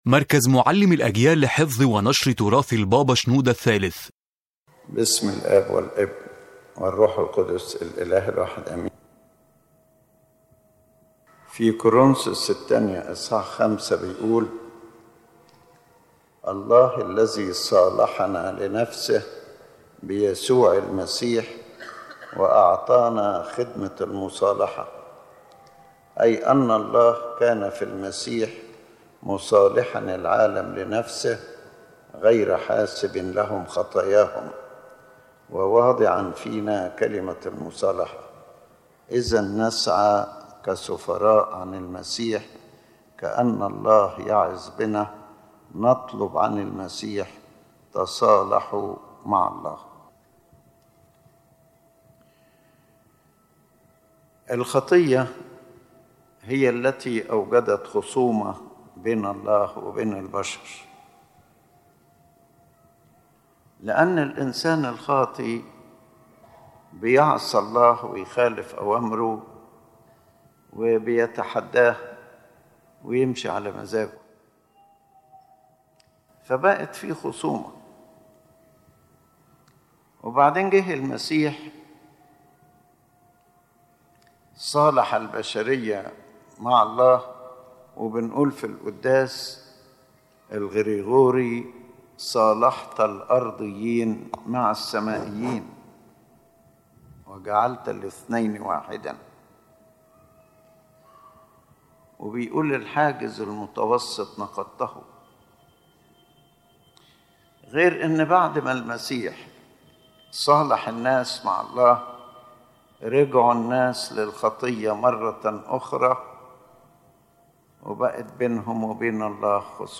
The Main Idea of the Lecture